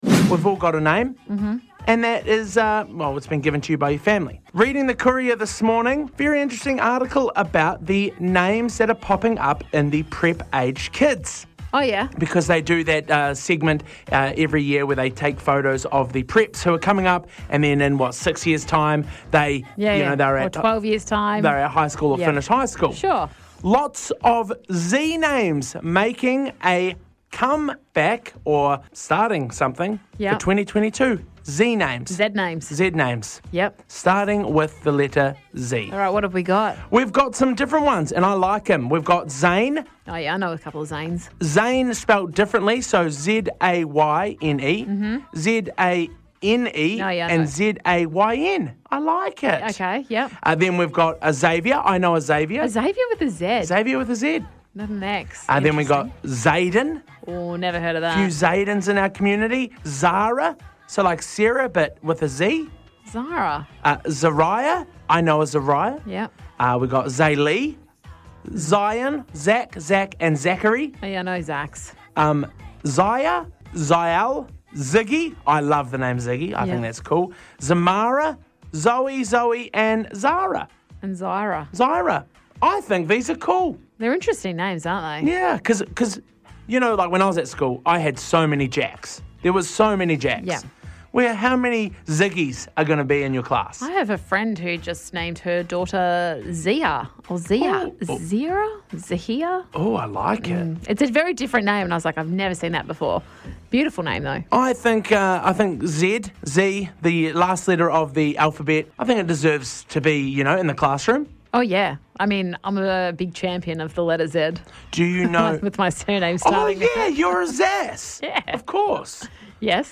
The guys talk about the names that start with Z around our area